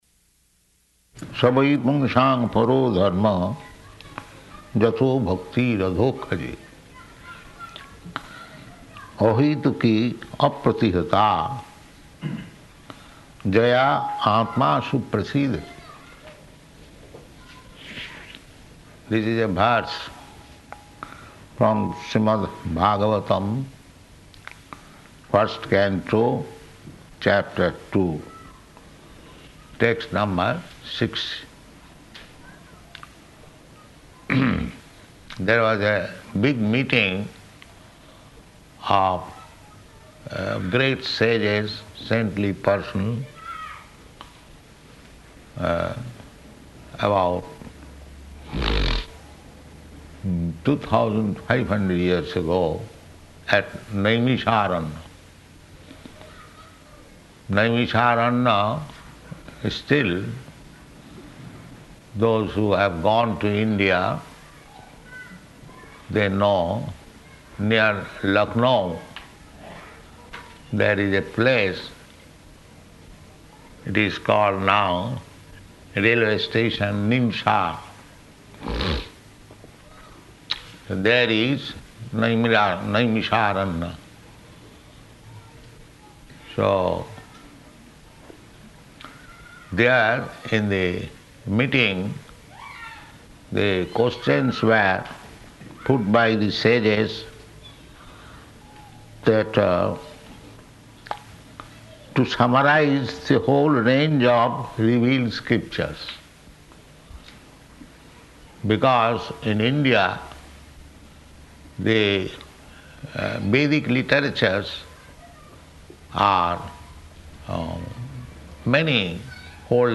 Location: Mauritius
[children shouting outside] [aside:] It is not possible to stop them?